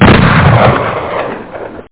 PSION CD 2 home *** CD-ROM | disk | FTP | other *** search / PSION CD 2 / PsionCDVol2.iso / Wavs / 44MAGNUM ( .mp3 ) < prev next > Psion Voice | 1998-08-27 | 15KB | 1 channel | 8,000 sample rate | 2 seconds
44MAGNUM.mp3